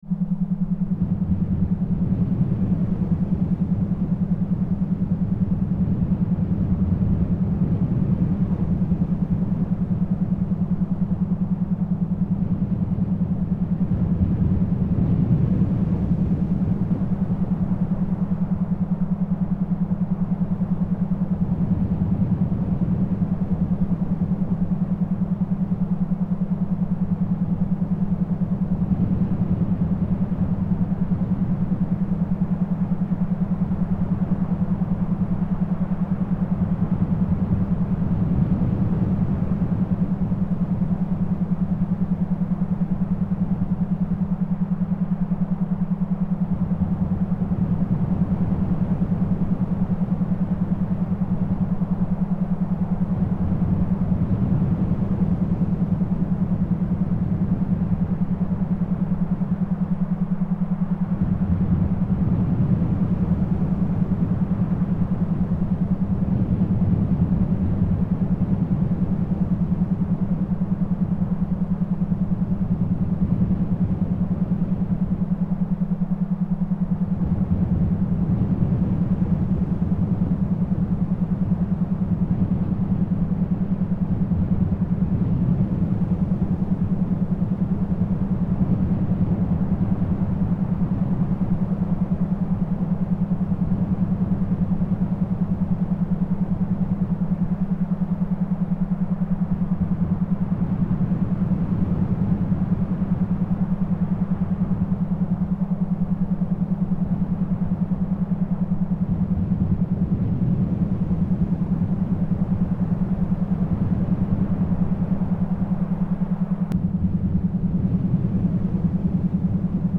4. Binaural Beats kostenlos Download –
Für tiefere Entspannung und Gelassenheit (Alphafrequenz, 8-12 Hz)
kostenlos-entspannung-und-ausgeruht-4min.mp3